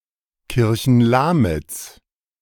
Kirchenlamitz (German: [kɪʁçn̩ˈlaːmɪt͡s]